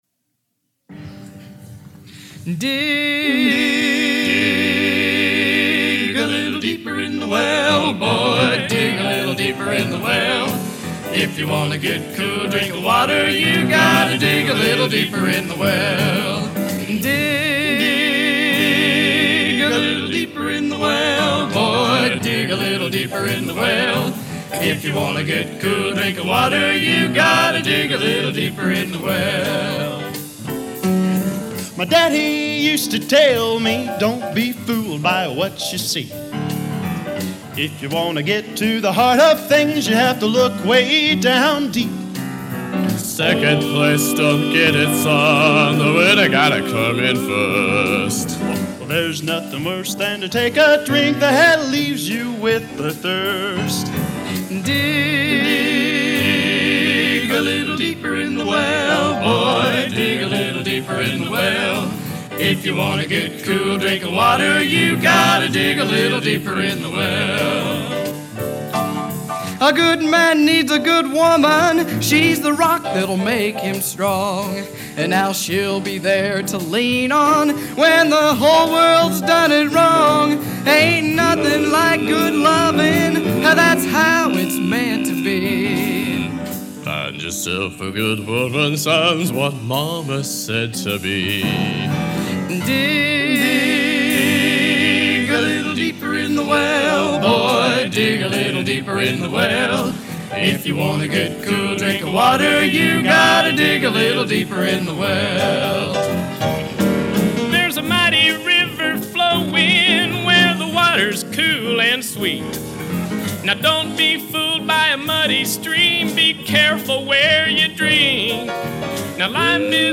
Genre: Gospel Traditional | Type: End of Season